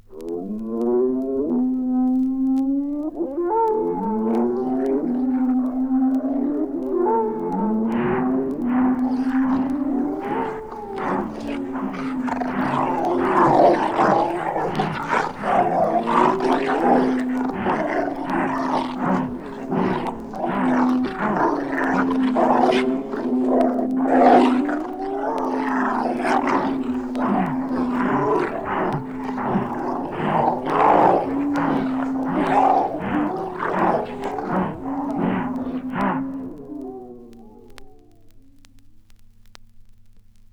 • wolves howling and snarling.wav
Recorded from Sound Effects - Death and Horror rare BBC records and tapes vinyl, vol. 13, 1977.